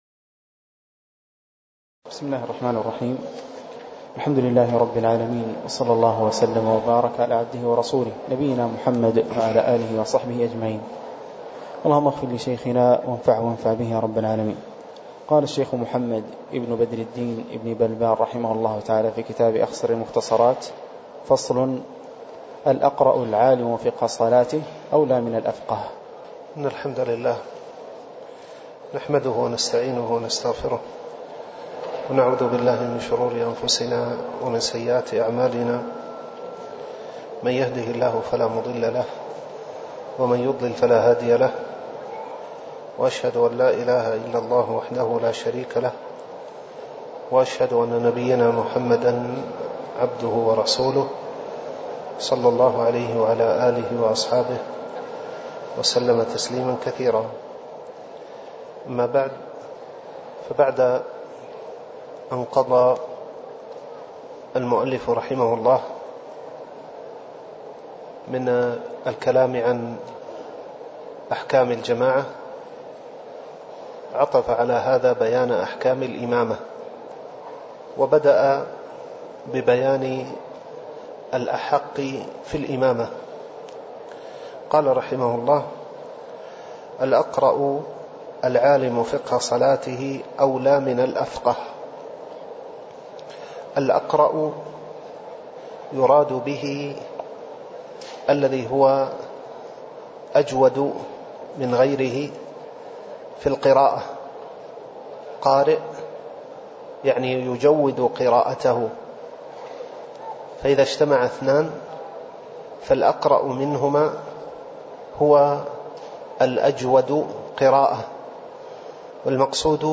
تاريخ النشر ٣ رجب ١٤٣٩ هـ المكان: المسجد النبوي الشيخ